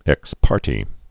(ĕks pärtē)